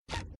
FootstepHandlerClown1.wav